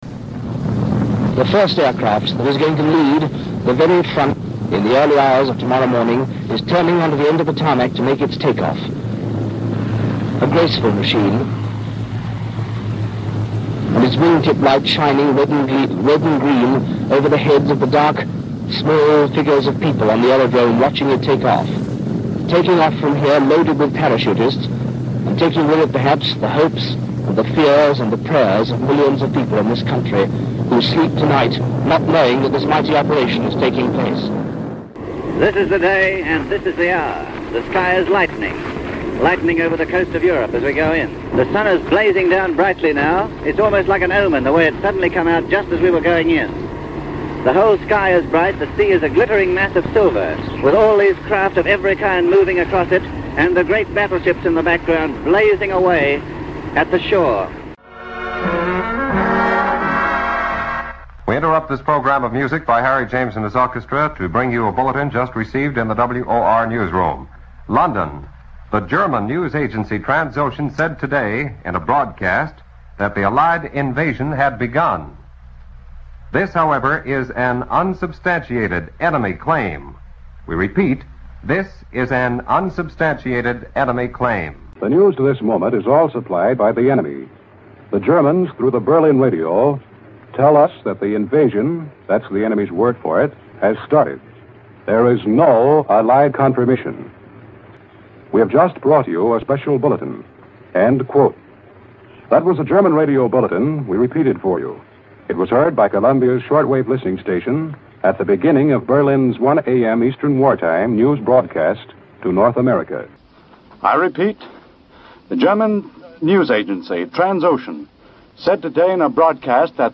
As you'll hear on this audio, American radio picked up on those broadcasts. The special BBC news bulletin came two-and-a-half hours later, presented, as you'll hear, by the reader who'd announced so much Twentieth Century history, John Snagge. The media was, nevertheless, well prepared; and you can also hear here Richard Dimbleby watching the airborne troops take off.